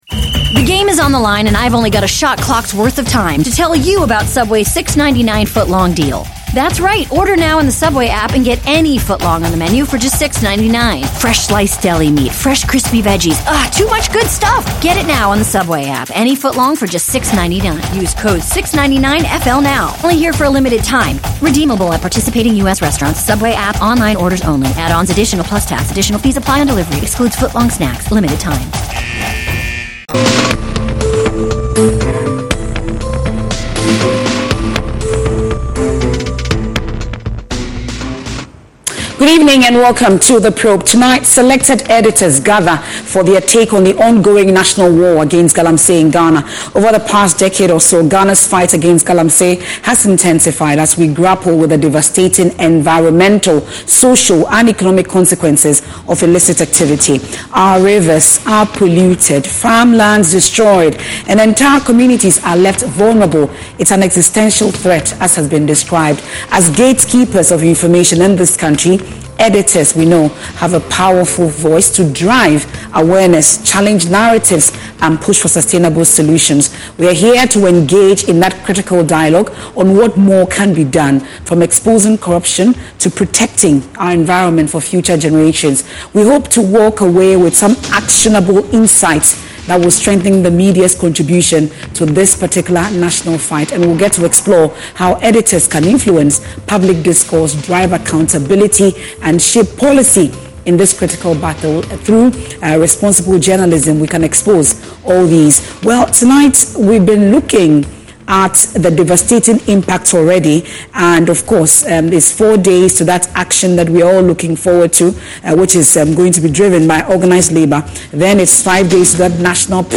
What would you ask a politician if you had the chance? Welcome to The Probe, an audience-driven news interview program that collects questions from the general public and demands answers from political actors, duty bearers and relevant stakeholders.